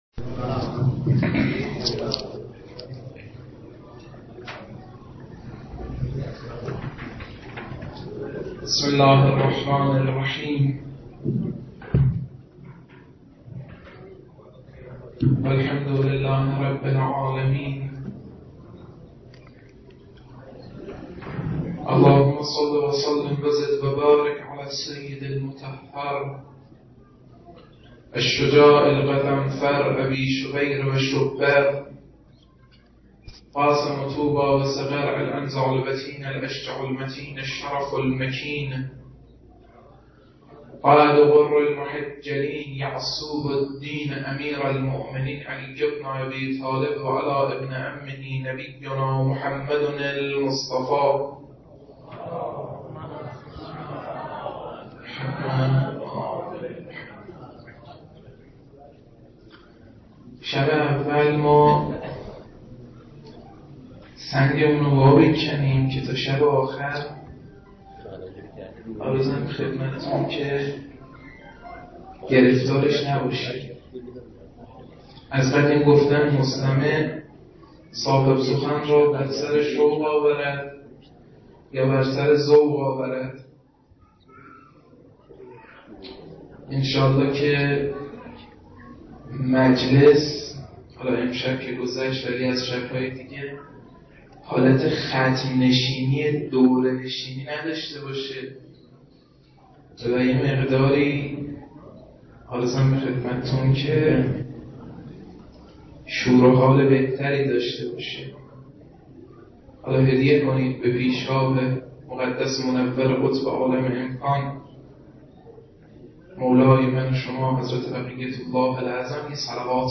سخنرانی های شب اول تا سوم با موضوع تقابل عقل و جهل در کربلا.